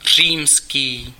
Ääntäminen
IPA : /ˈɹoʊmən/